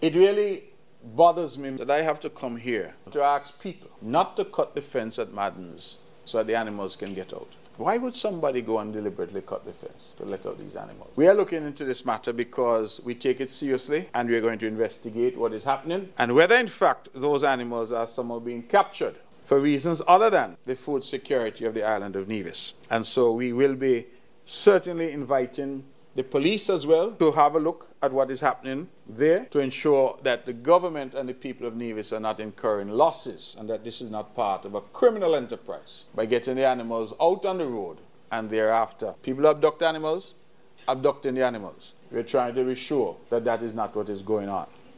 During his press conference on Tuesday, Premier Mark Brantley revealed that the fence surrounding the property in St. James had been cut, allowing cattle to roam free throughout the surrounding areas. Minister Brantley spoke to further destruction which this potentially brings including traffic accidents, damage to private properties and financial loss to the Nevis Island Administration.